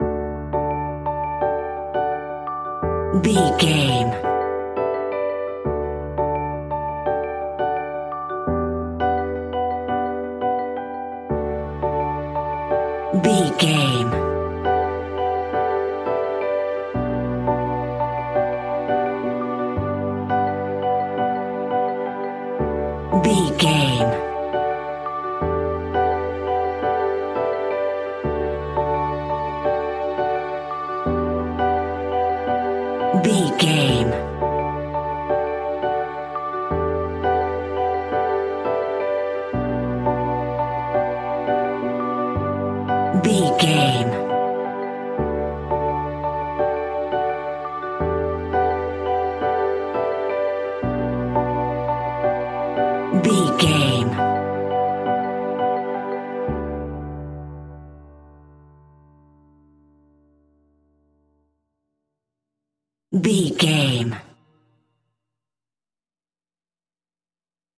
Ionian/Major
D
pop rock
indie pop
energetic
uplifting
upbeat
guitars
bass
drums
piano
organ